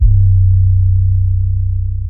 808_hHeavy.wav